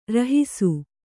♪ rahisu